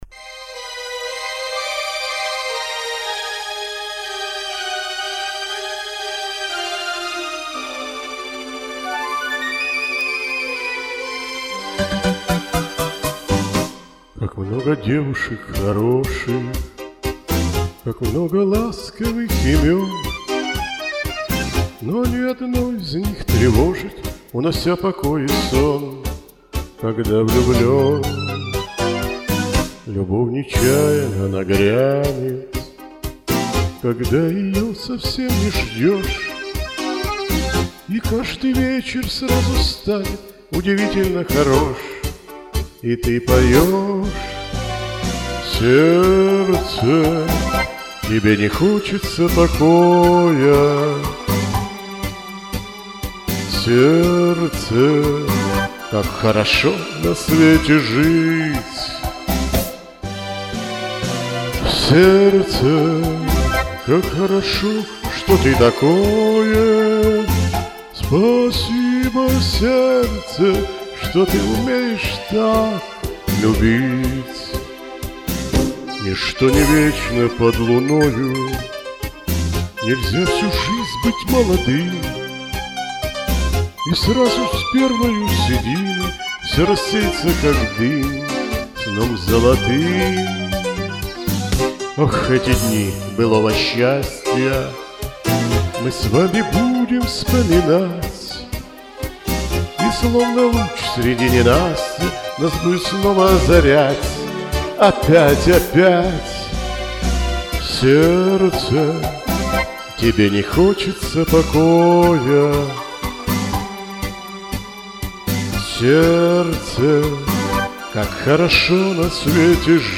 В поединке запрещено оценивать и комментировать тем, у кого менее 5 записей вокала!